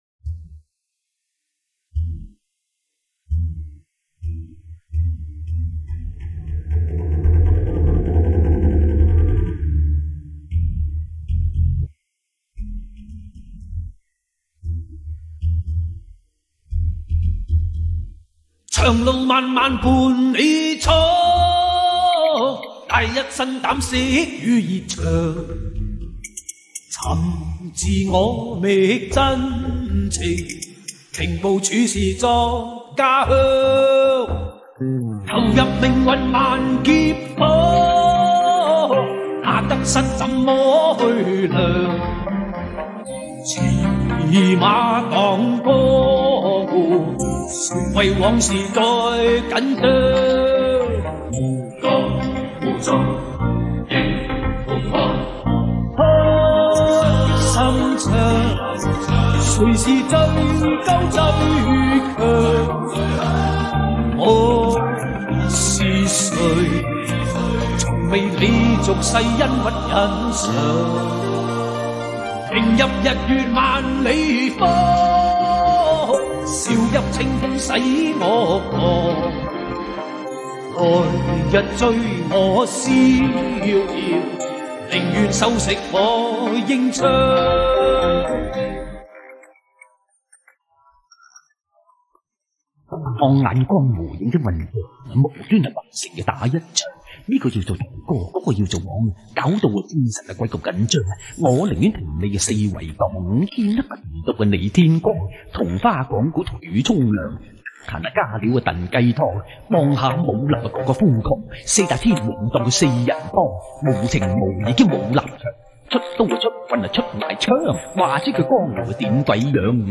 经典歌曲